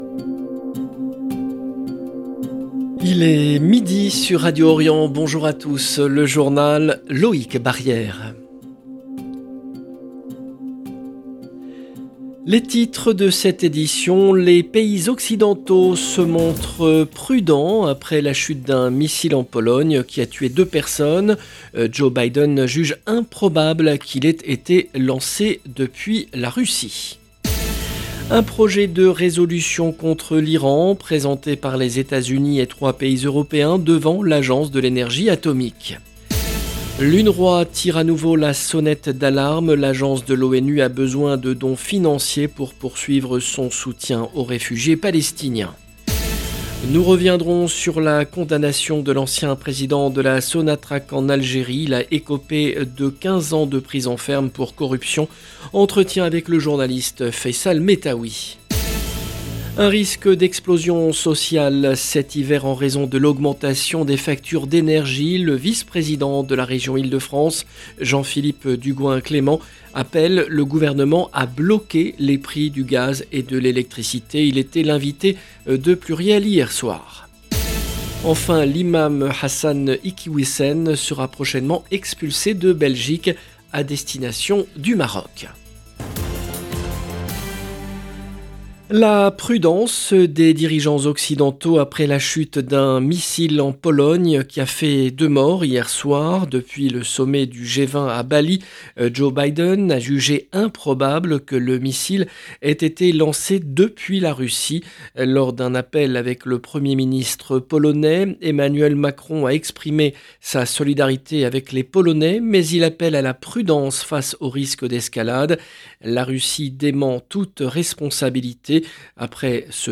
Journal présenté